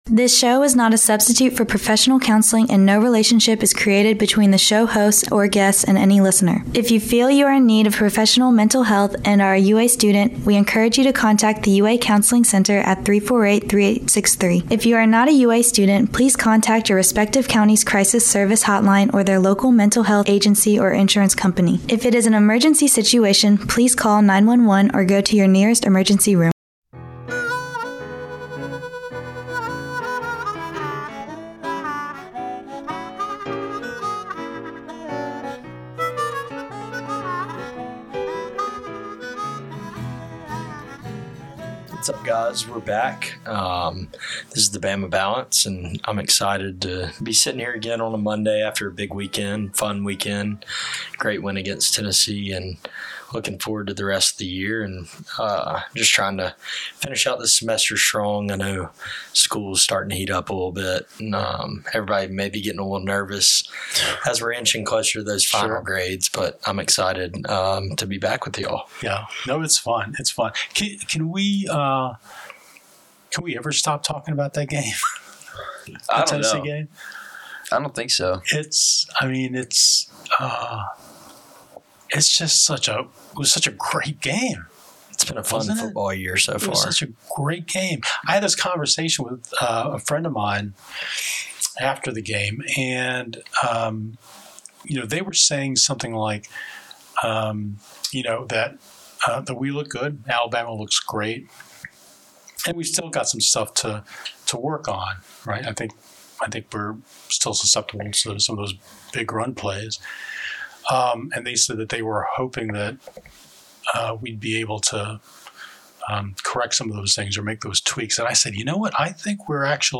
The Bama Balance S02.E09: A Conversation On Suicide